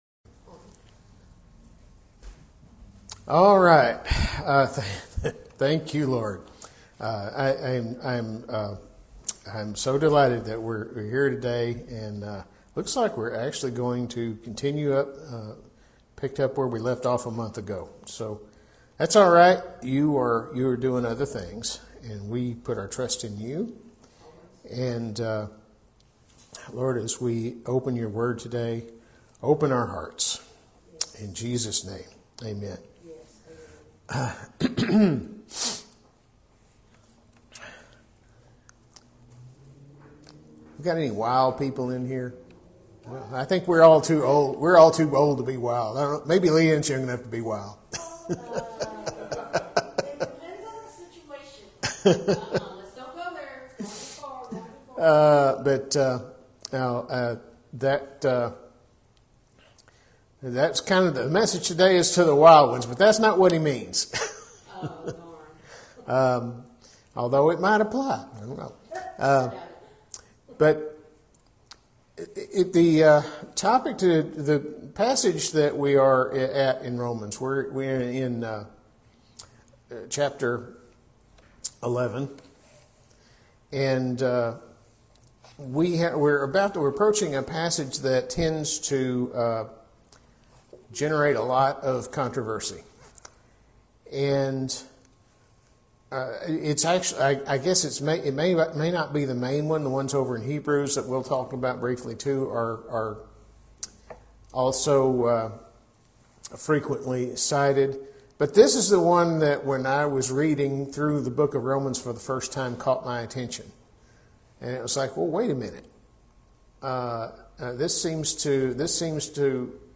Message from April 12: Words to the Wild Ones